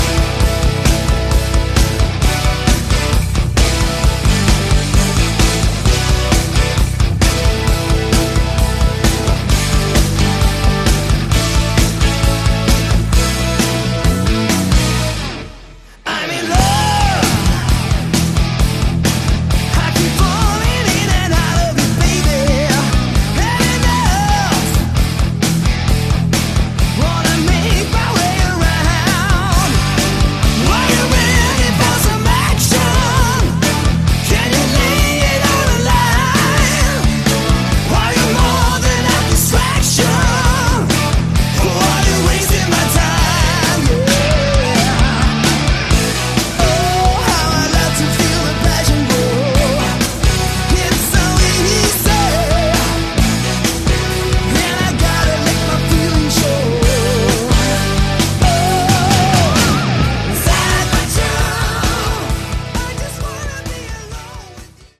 Category: AOR
guitar
drums
bass
backing vocals